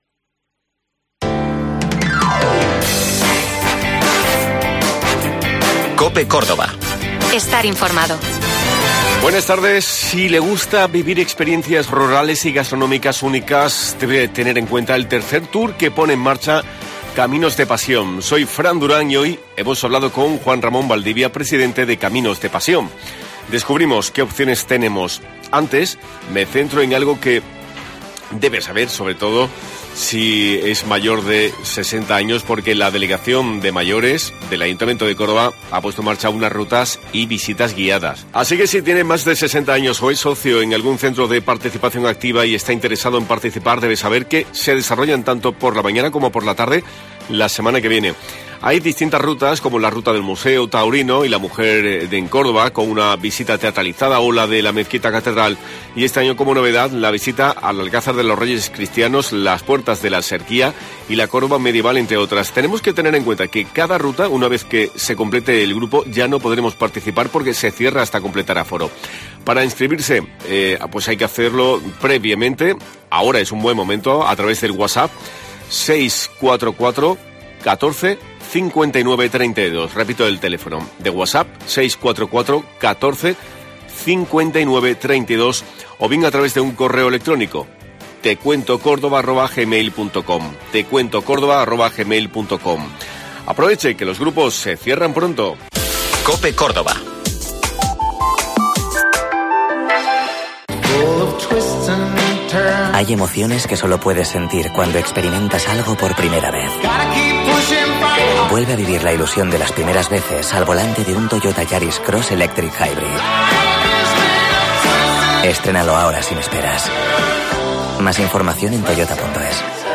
LA ACTUALIDAD CADA DÍA